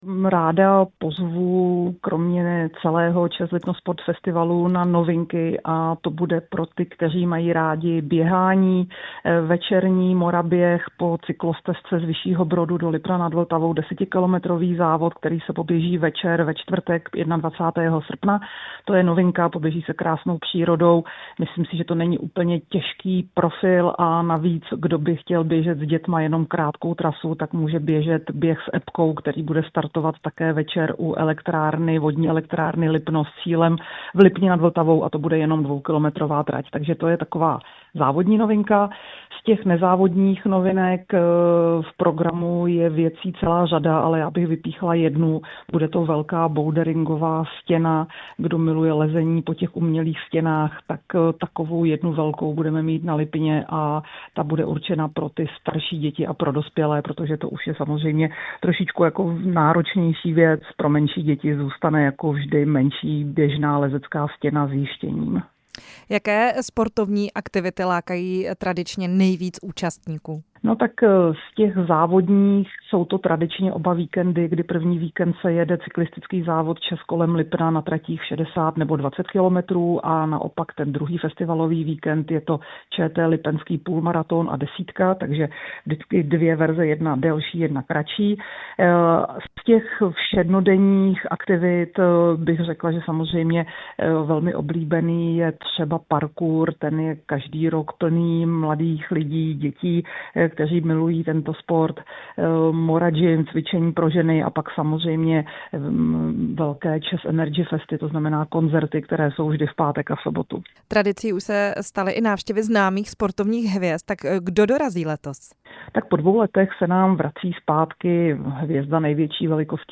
Tradiční srpnový Lipno Sport Festival letos láká na nové aktivity. Na co se návštěvníci v okolí lipenské přehrady mohou těšit nám ve vysílání Rádia Prostor přiblížila ředitelka Festivalu Kateřina Neumannová.
Rozhovor s ředitelkou Lipno Sport Festivalu Kateřinou Neumannovou